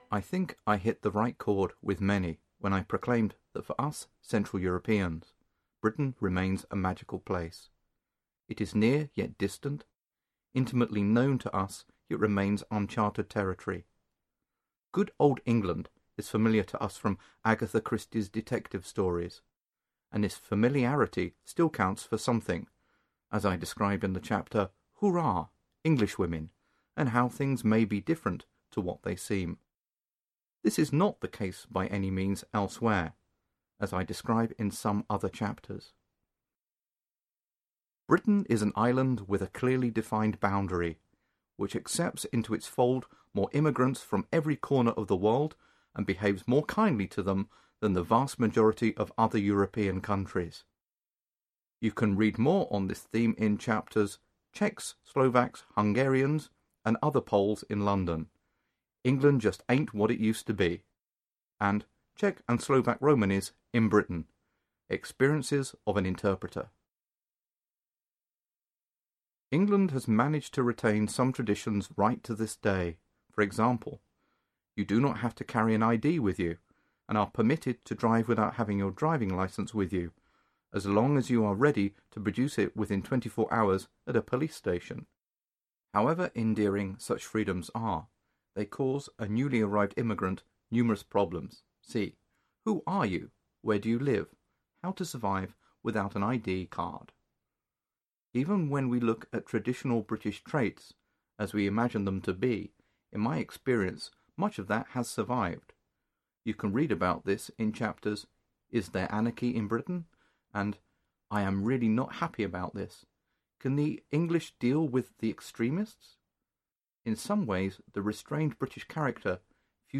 Audio kniha
Ukázka z knihy
Celý text je namluven rodilými mluvčími.